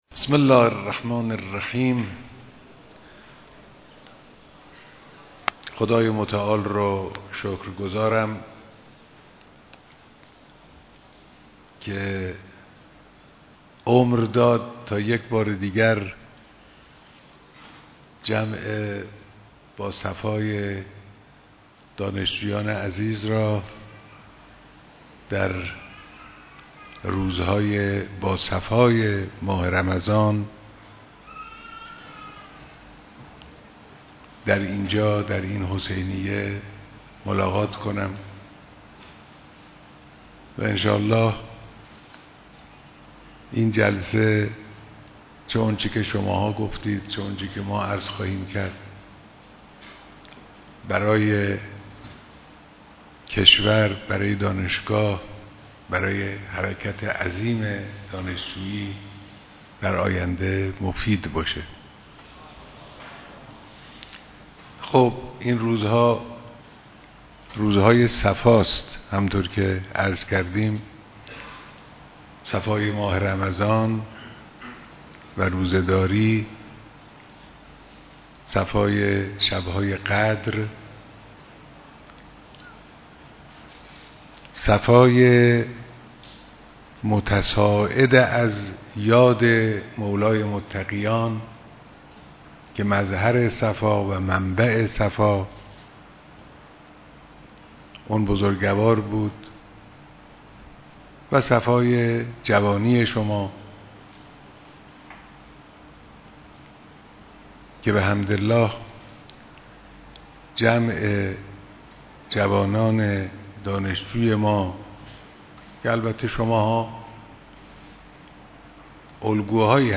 بیانات در دیدار جمعی از دانشجویان و نمایندگان تشکل‌های دانشجویی